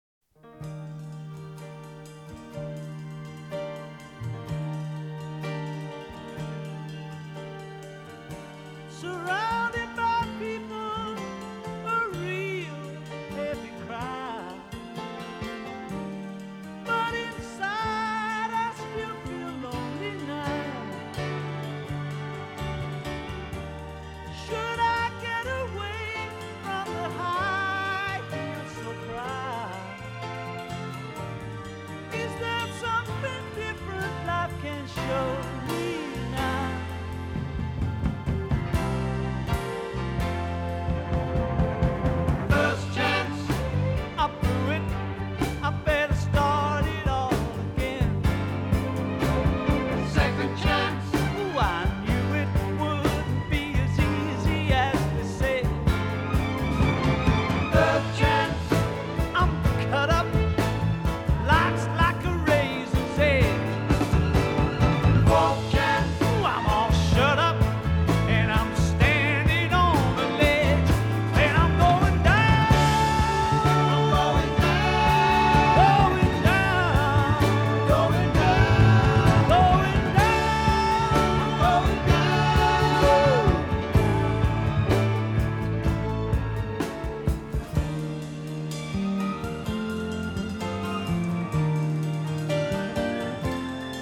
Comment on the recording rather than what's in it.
It seems slightly more "open".